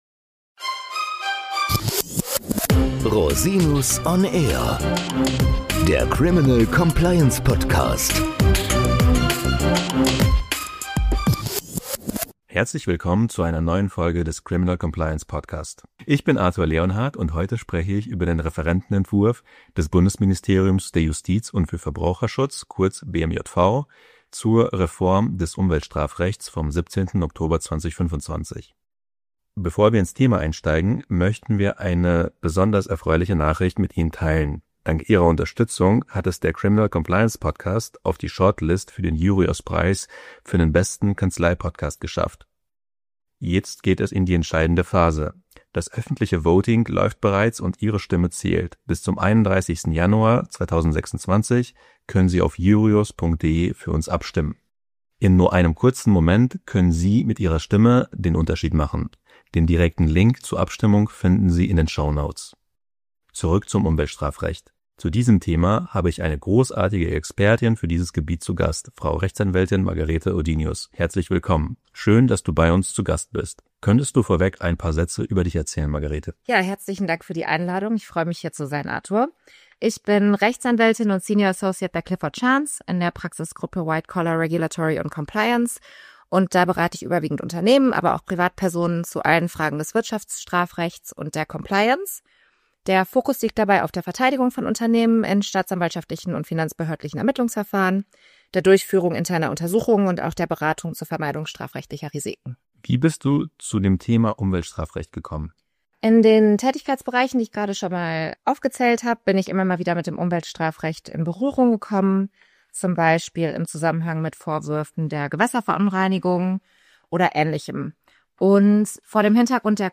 Update Umweltstrafrecht – Interview